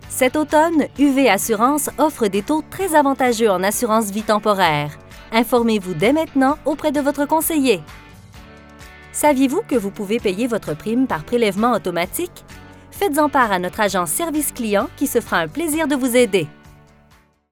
attente téléphonique